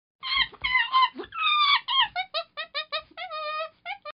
Tiếng con Khỉ con kêu MP3